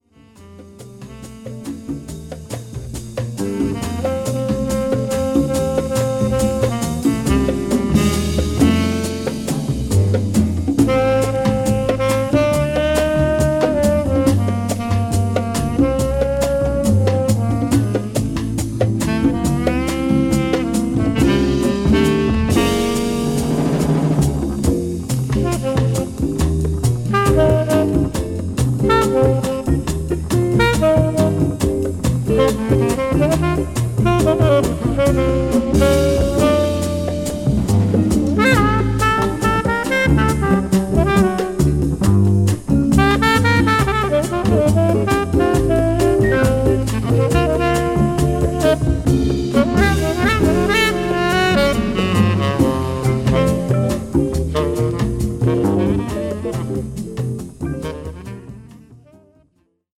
ブルージーな曲からラテン・テイスト、ファンキー、ムーディーまで、オルガン・ジャズの旨みが堪能できる名作です。